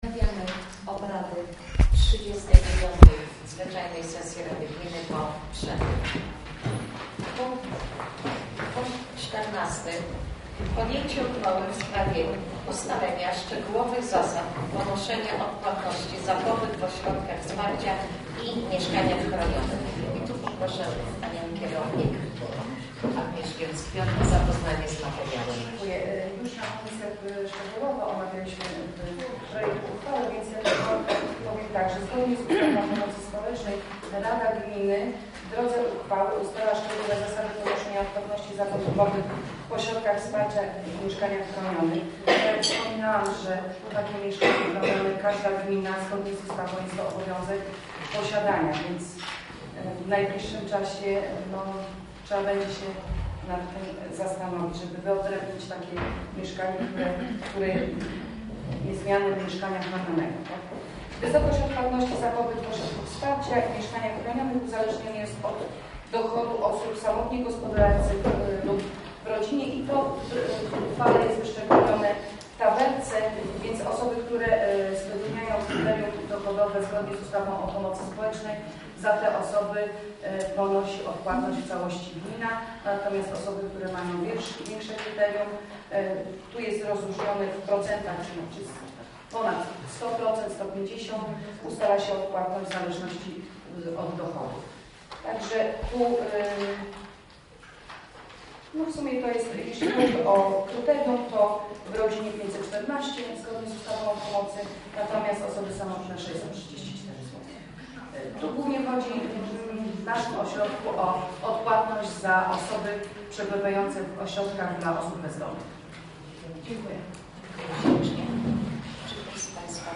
Nagrania audio z sesji Rady Gminy Jedwabno kadencja VII 2014-2018